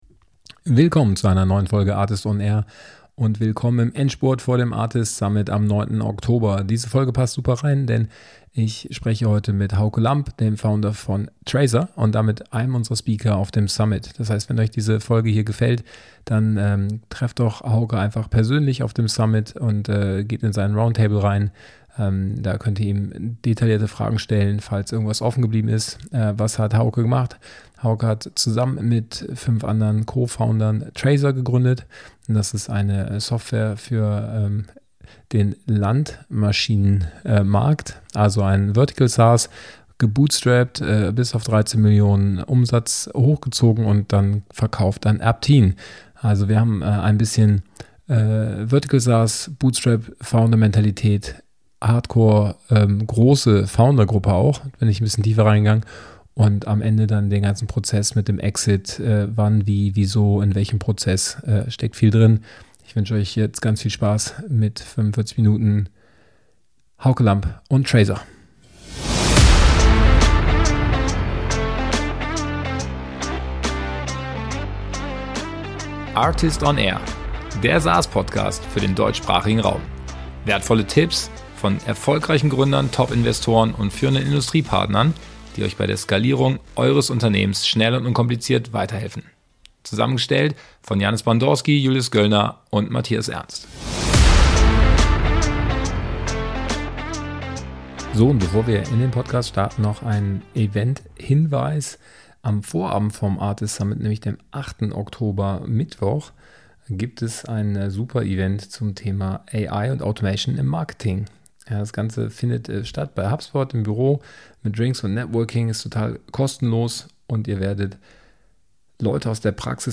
We talk about building software companies in Europe. In the ARRtist on AIR podcast, successful B2B SaaS founders, top investors, leading industry partners and experts provide their hard learnings, valuable tips and insights beyond the obvious.